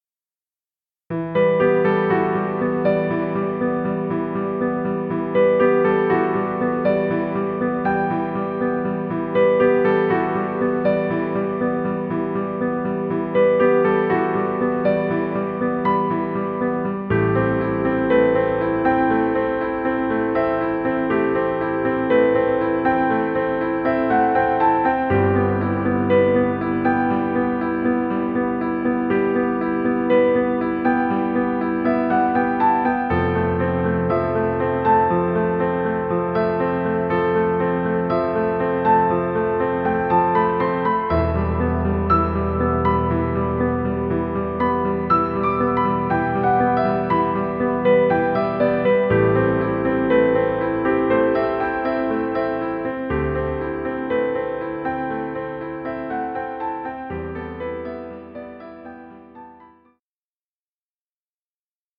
Romantic piano music.
Stock Music.